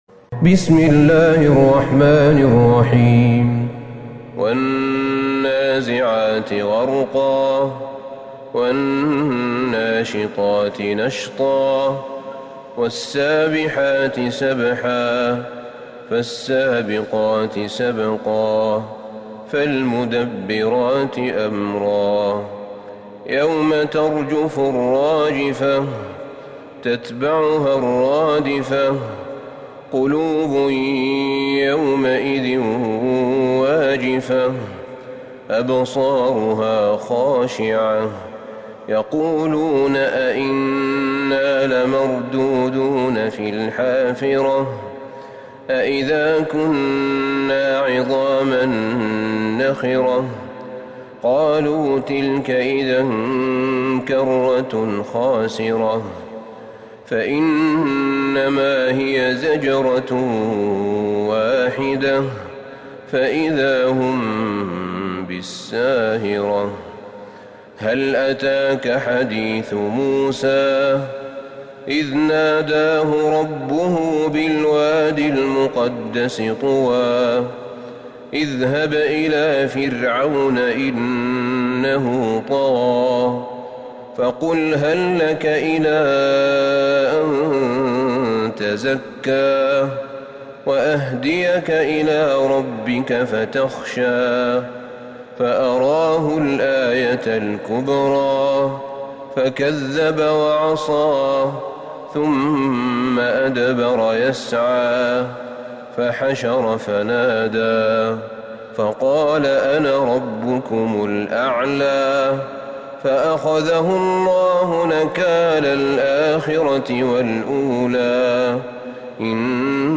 سورة النازعات Surat An-Naziat > مصحف الشيخ أحمد بن طالب بن حميد من الحرم النبوي > المصحف - تلاوات الحرمين